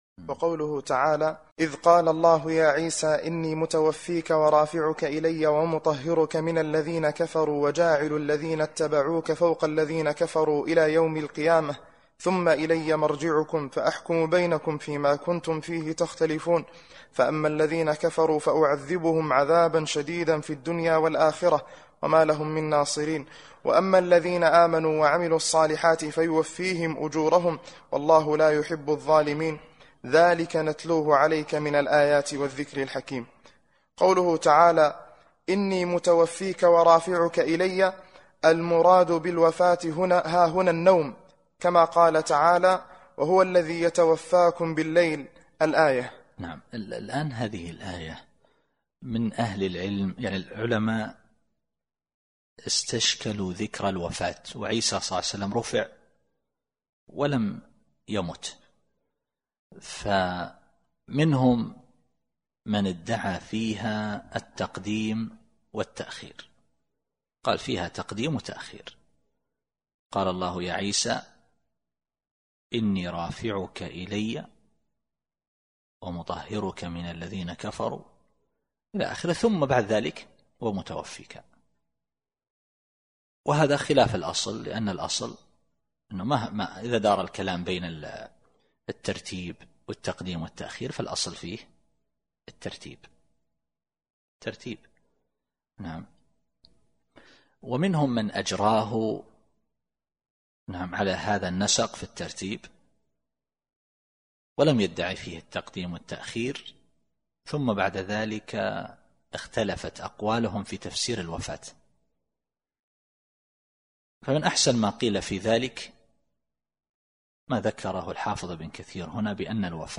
التفسير الصوتي [آل عمران / 55]